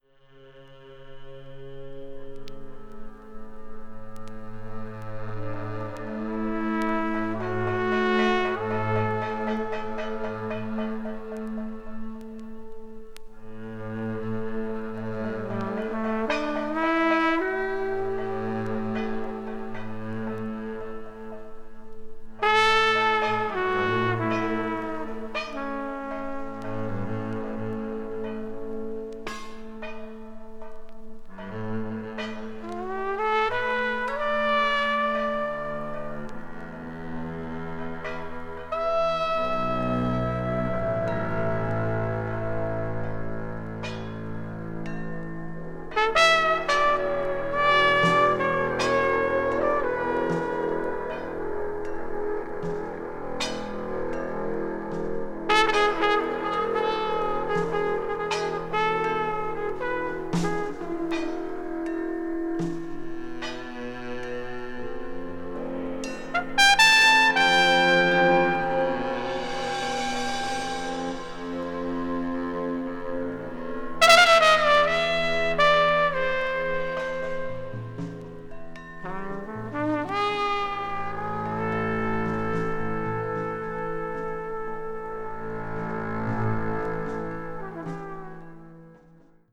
media : EX/EX(some slightly noises.)
floats deeply in the background on tracks like A4.